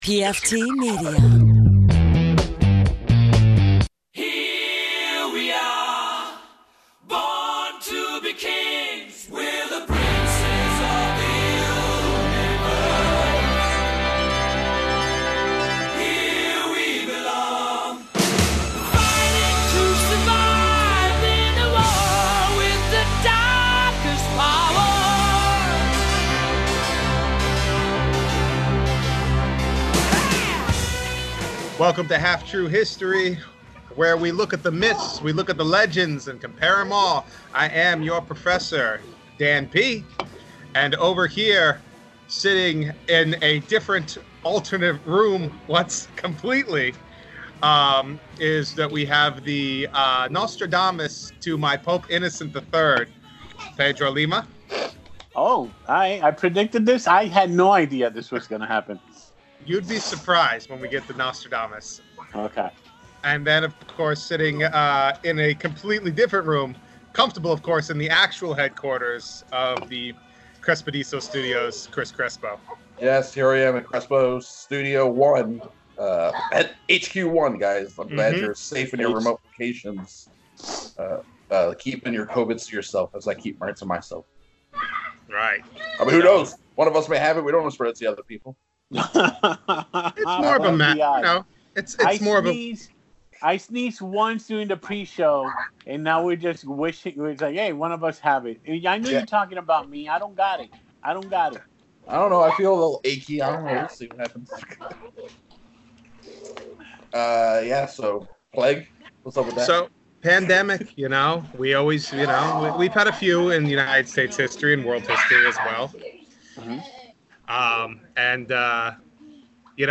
(Pre-recorded before the pandemic shut everything down)